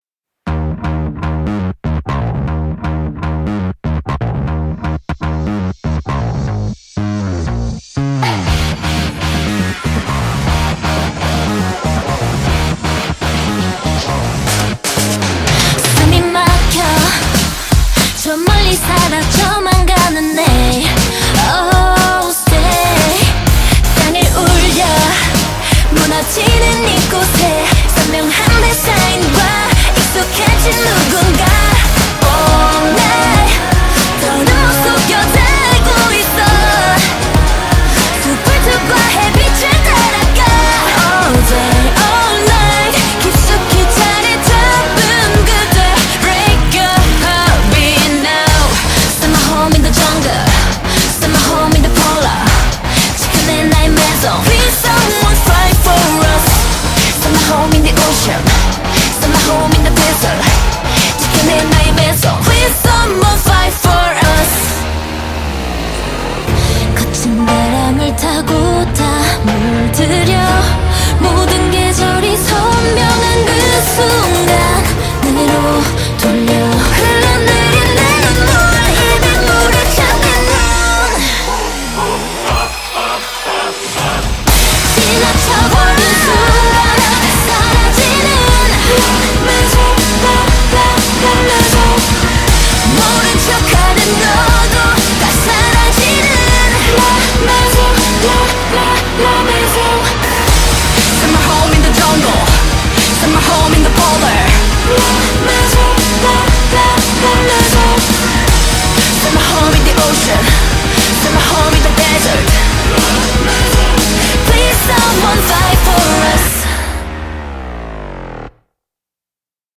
BPM120-240
Audio QualityPerfect (High Quality)